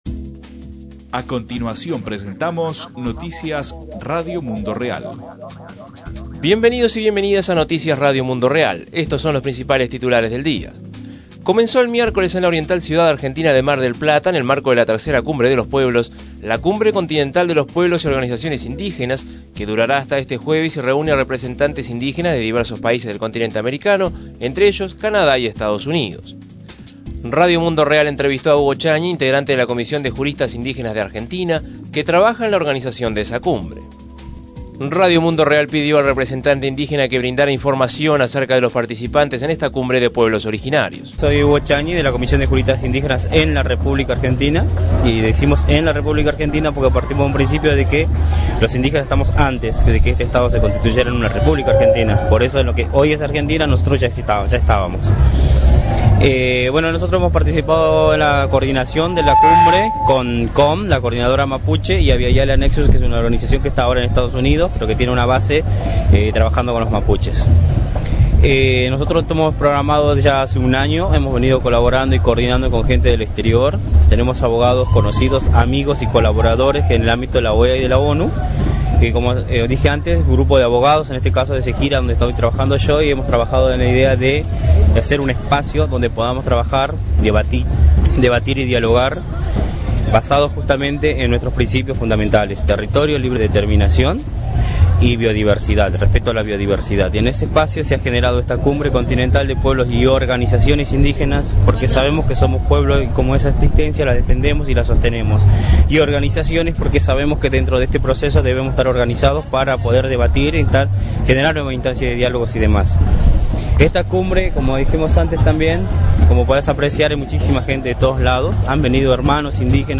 Cumbre de las Américas - Mar del Plata, Argentina 4 y 5 de Noviembre de 2005
Radio Mundo Real pidió al representante indígena que brindara información acerca de los participantes en esta cumbre de pueblos originarios.